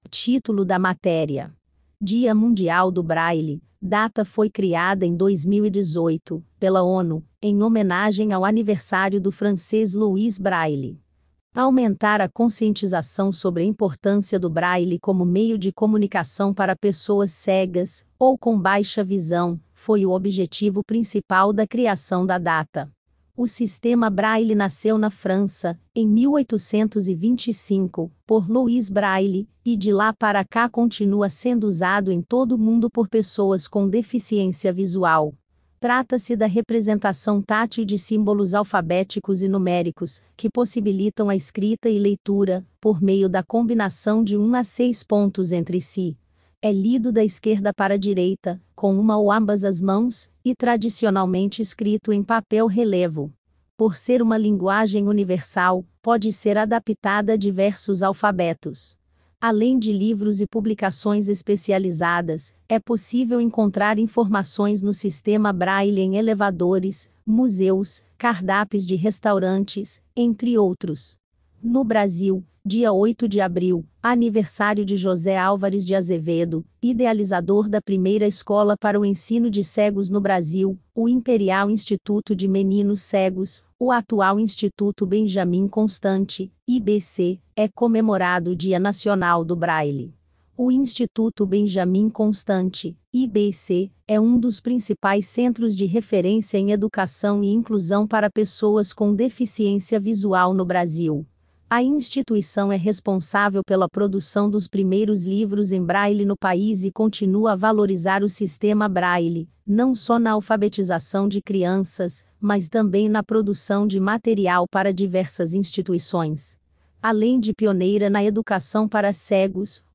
Acompanhe a matéria tambem em audio
DIA_MUNDIAL_DO_BRAILE_IA.mp3